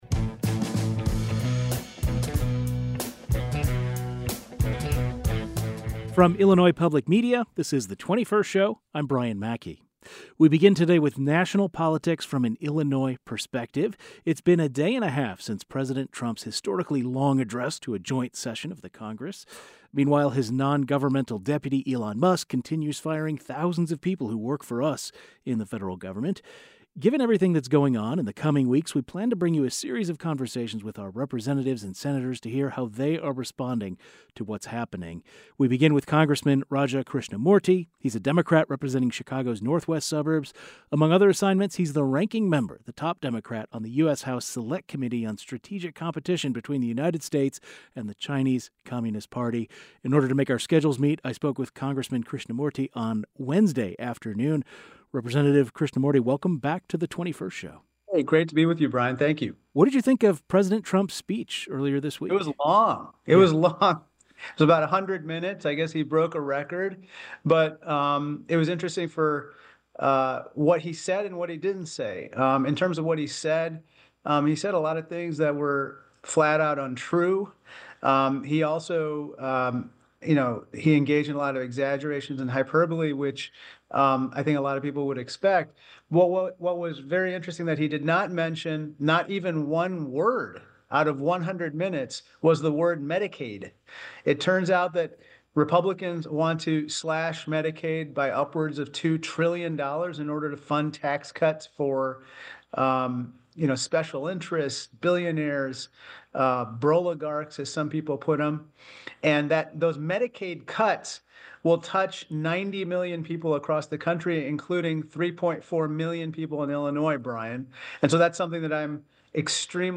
Rep. Raja Krishnamoorthi (D-IL8) US House of Representatives